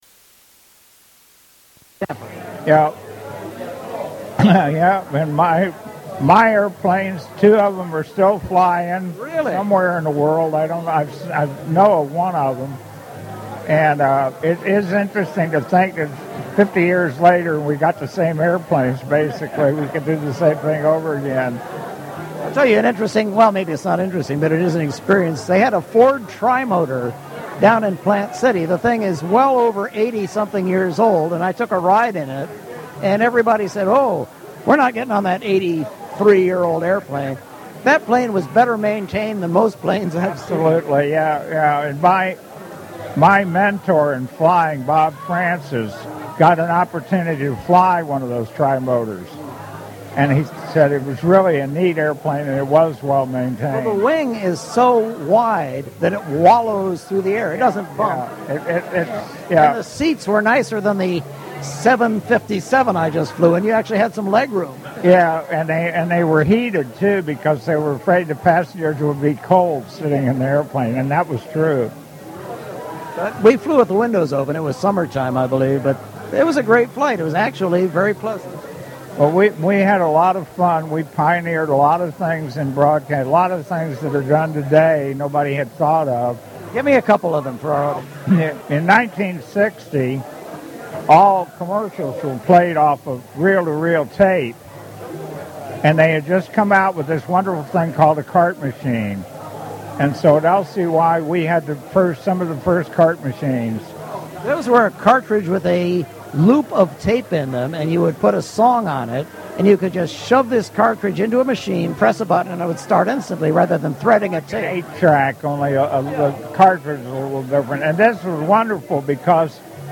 Tampa Bay Radio Reunion Part 4 11-8-14 Live from Oldsmar!